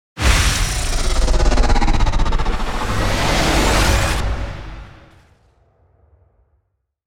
Download Free Energy Sound Effects | Gfx Sounds
Time-warp-slow-motion-portal-3.mp3